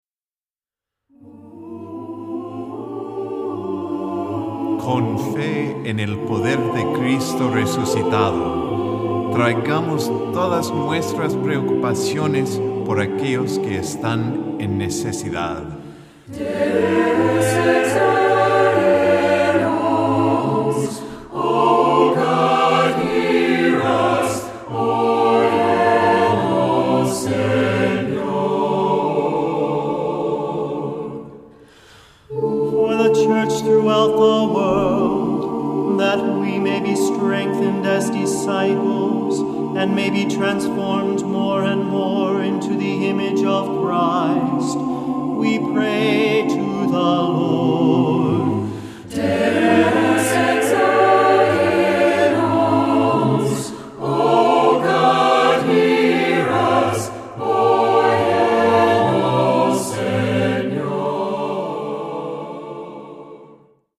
Voicing: SATB; Cantor